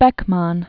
(bĕkmän), Max 1884-1950.